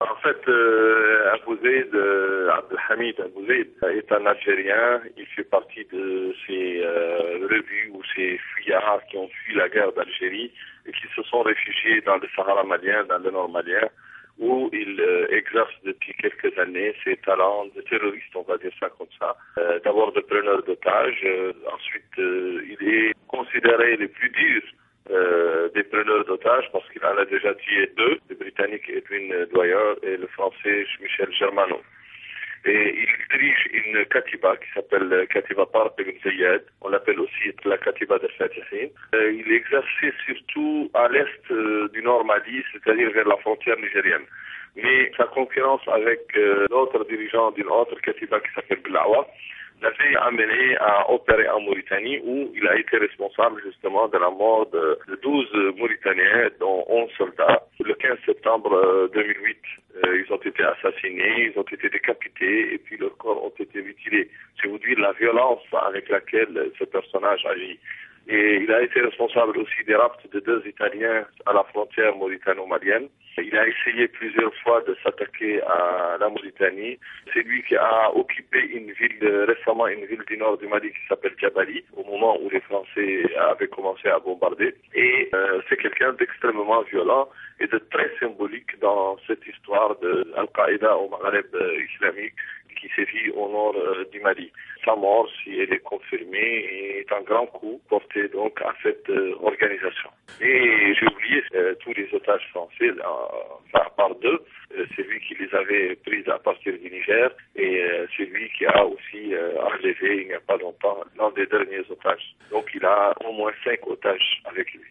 Un confrère basé en Mauritanie nous dit qui est Abou Zeid et pourquoi on s’intéresse tant à son sort.